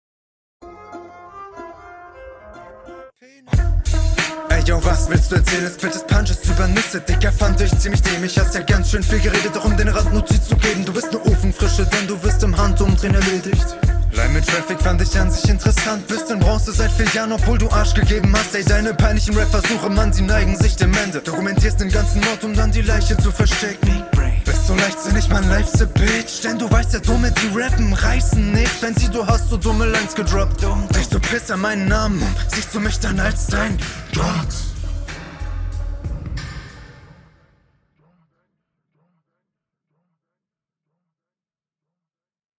Flowst etwas besser und hast teilweise echt gute Konter dabei.